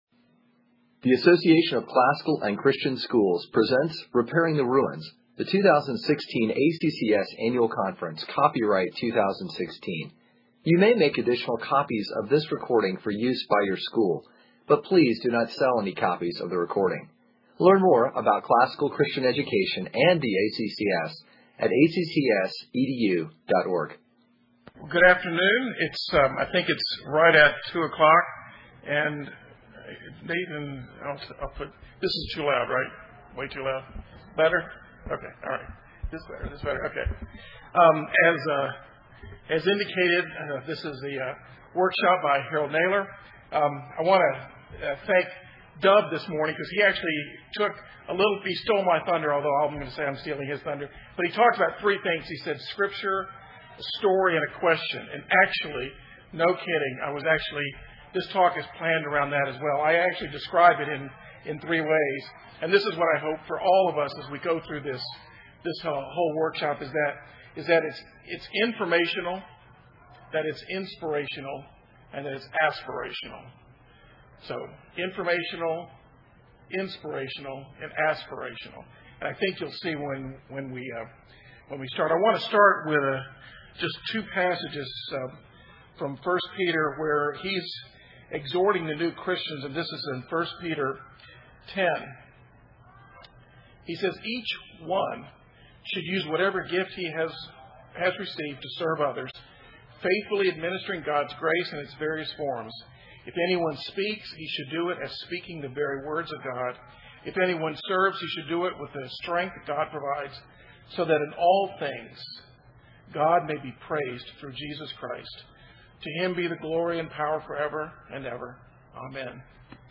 2016 Leaders Day Talk | 33:49:00 | Fundraising & Development, Leadership & Strategic, Marketing & Growth